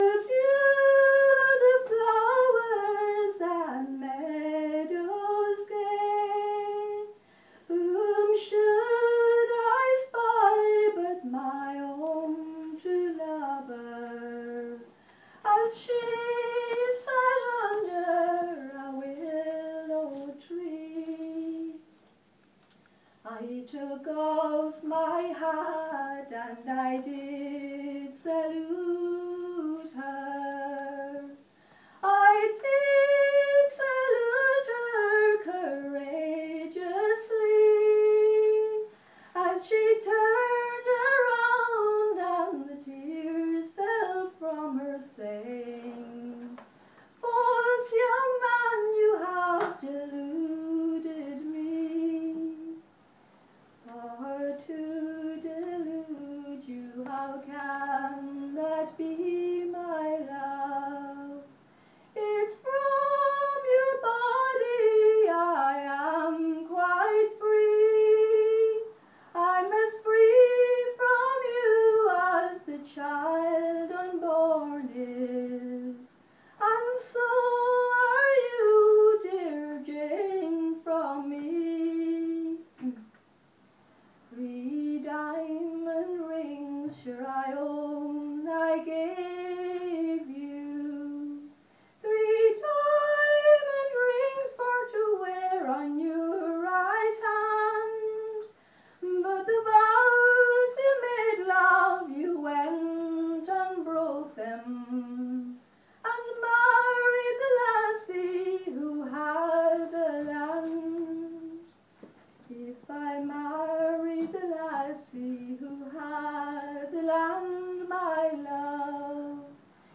Rathmines Writers Workshop 21st birthday reading